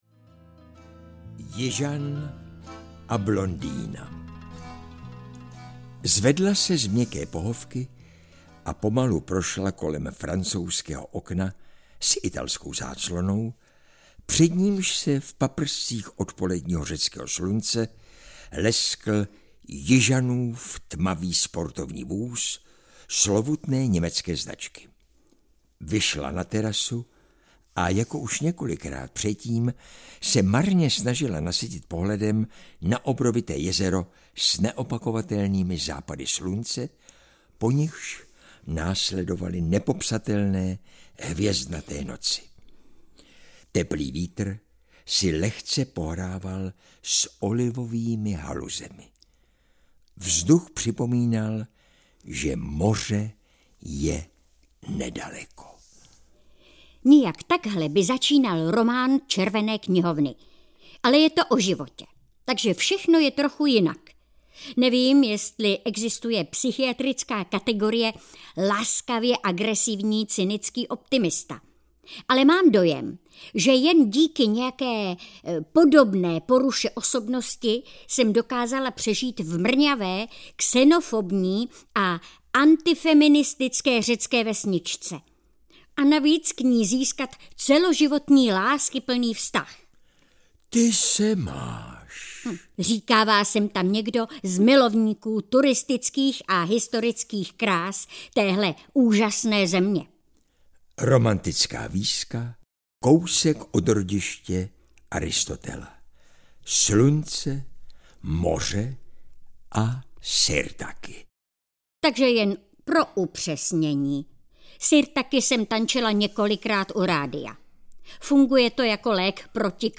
Jižan a blondýna audiokniha
Ukázka z knihy
• InterpretEva Hrušková, Jan Přeučil